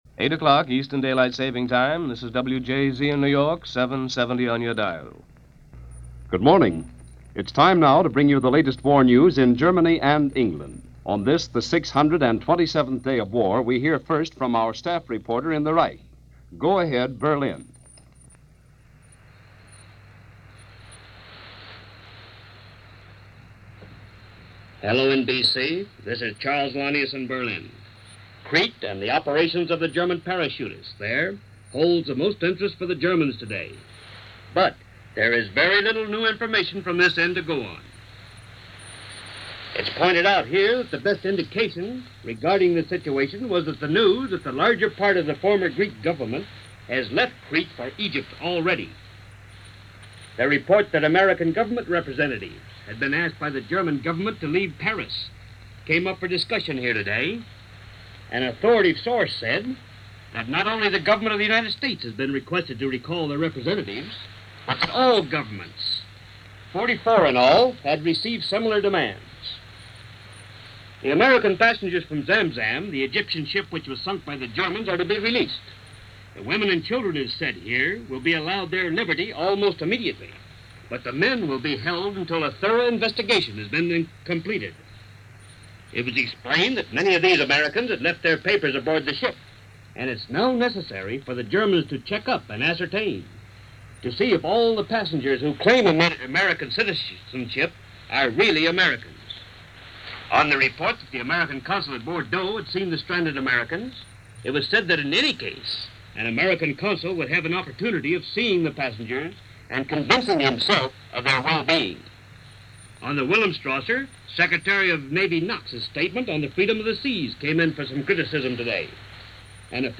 Invasion of Crete - May 22, 1941 - Past Daily news of the day for May 22, as reported by NBC Blue Network News Of The World.